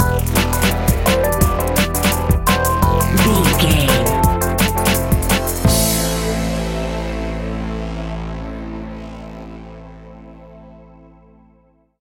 Aeolian/Minor
Fast
futuristic
hypnotic
industrial
dreamy
frantic
aggressive
powerful
synthesiser
drums
electronic
sub bass
synth leads
synth bass